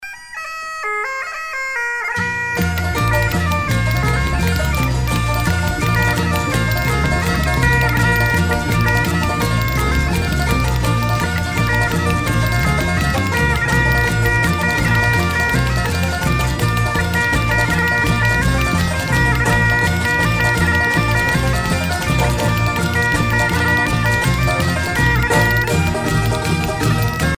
danse : gavotte bretonne
Groupe celtique
Pièce musicale éditée